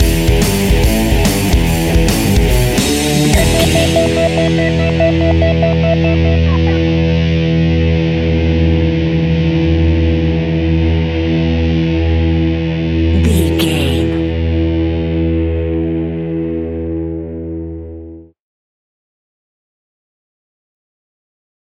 Epic / Action
Aeolian/Minor
heavy metal
distortion
instrumentals
Rock Bass
Rock Drums
heavy drums
distorted guitars
hammond organ